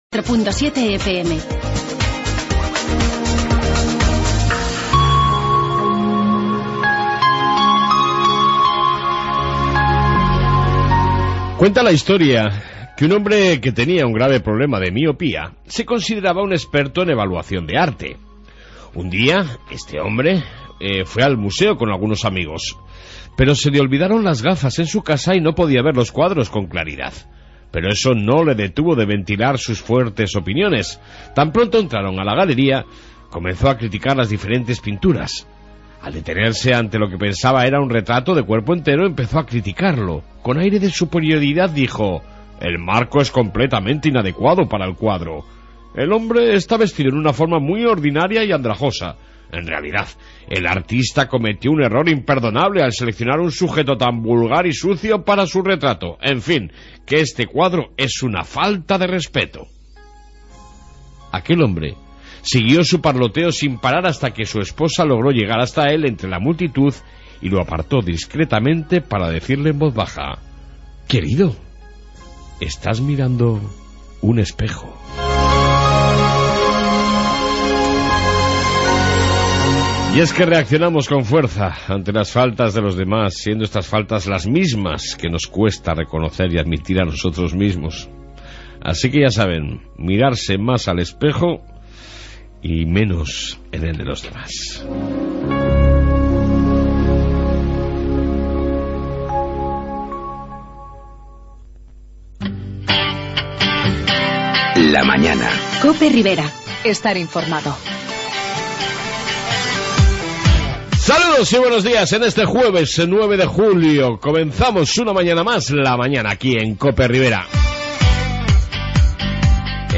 AUDIO: Reflexión diaria,Informe Policia municipal y amplia entrevista con el Alcalde de Peralta Juan Carlos Castillo